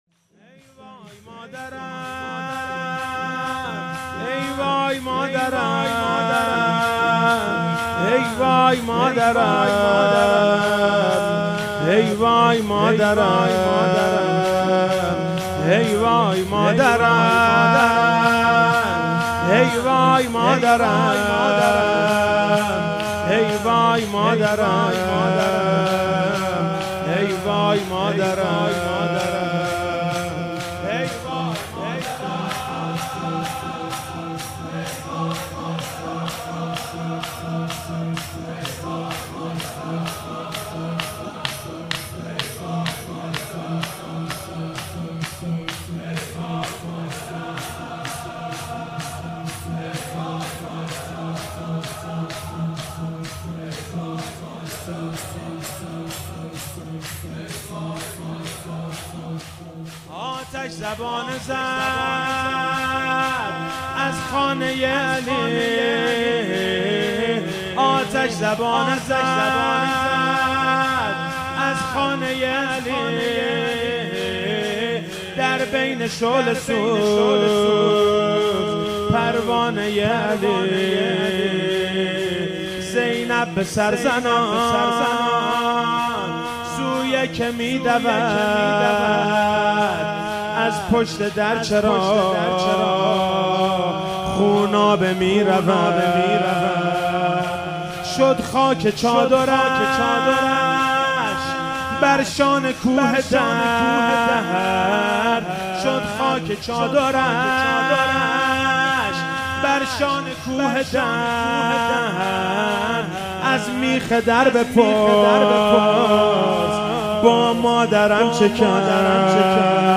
فاطمیه 96 - شب هفتم - شور - ای وای مادرم ای وای مادری